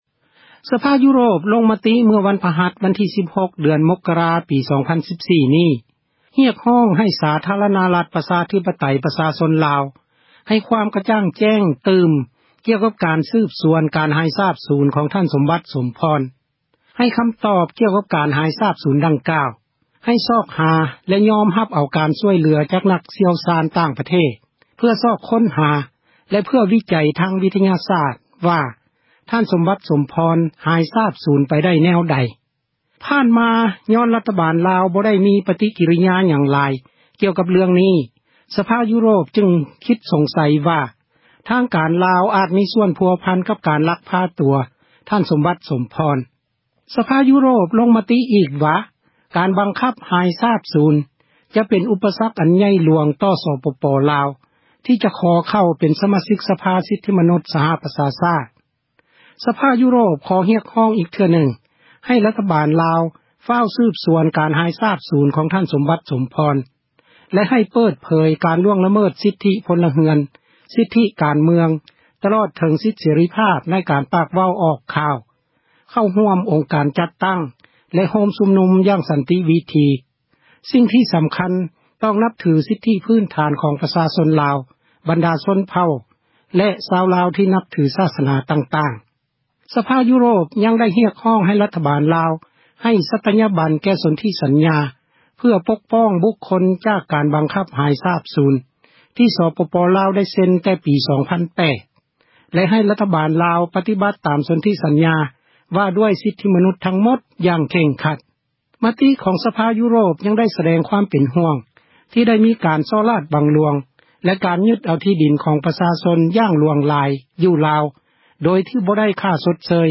Sombath Somphone: debate in European Parliament 7th Feb 2013. The case of Sombath Somphone was discussed at a plenary session of the European Parliament in Strasbourg on 7th February 2013.